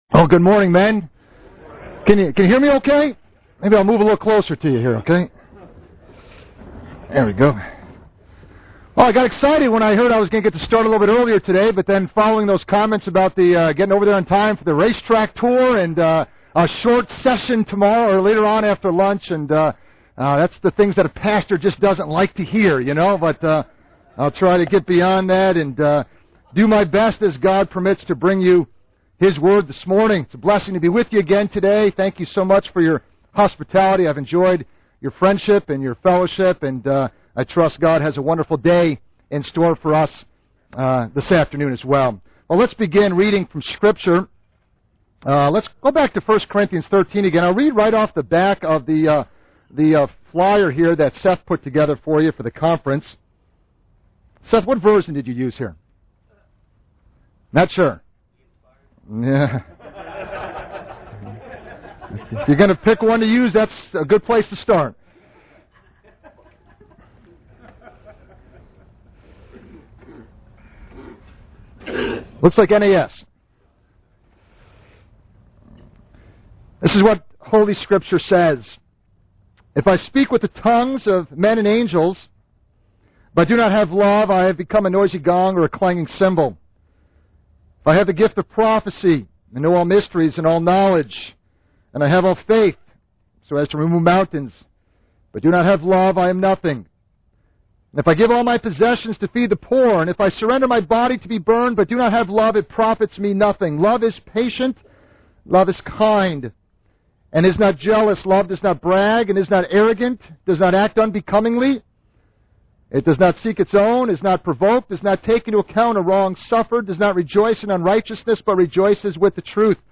Men Men's Retreat - 2006 Audio ◀ Prev Series List Previous 2.